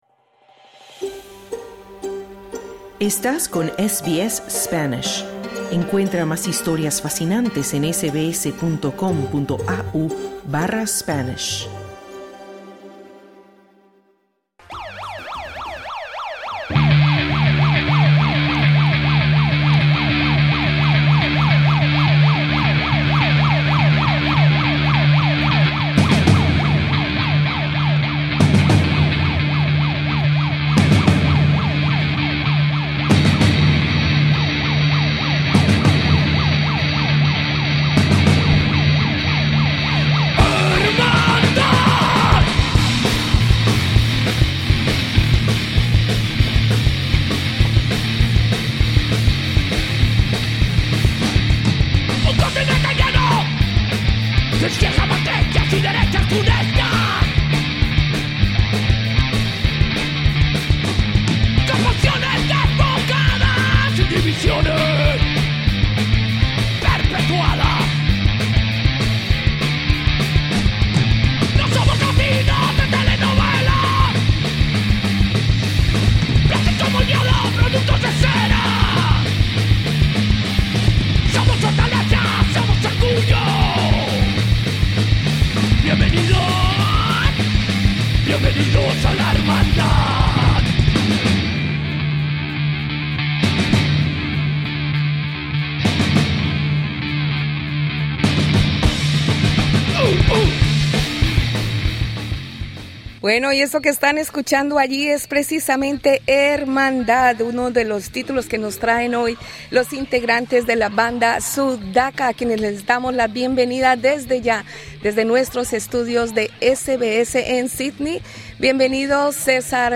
Entrevista con los integrantes de Sudaka, banda de rock basada en Sídney y que participa en Rocktubre 2025. Sus integrantes nos hablan de la orientación política y social de sus temas, de por qué se definen como una “arepa core” y del lanzamiento de su primer EP en 2026.